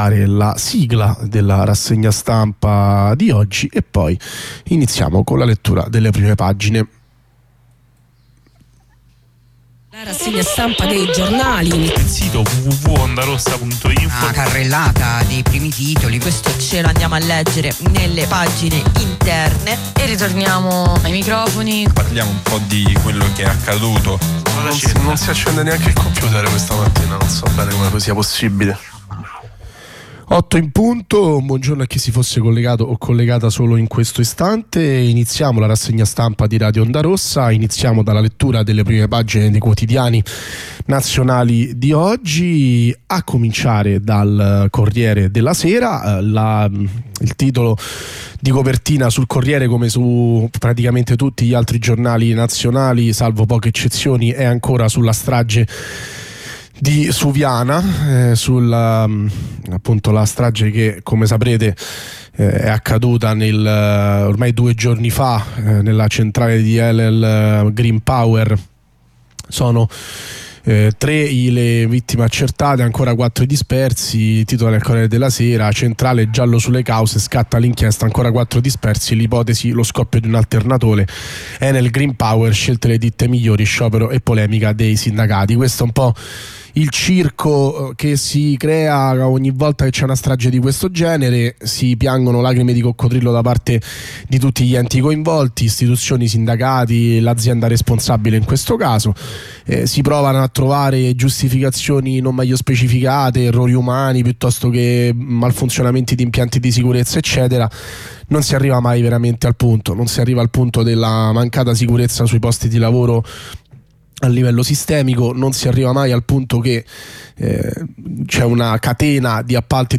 Rassegna stampa dell'11 aprile 2024